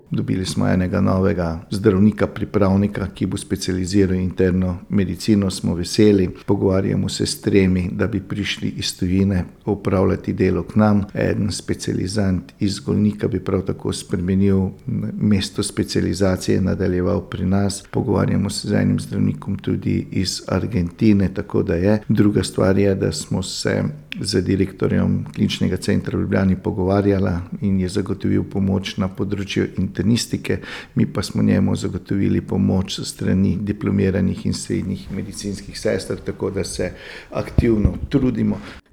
izjava internisti.mp3